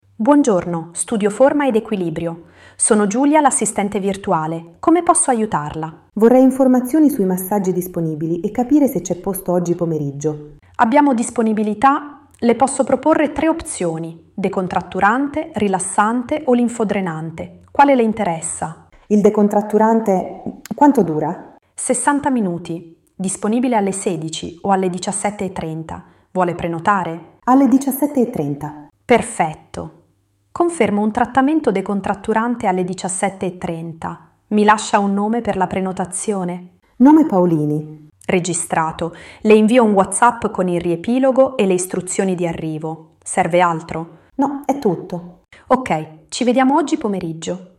Risponde con voce naturale e tono umano, senza suoni robotici.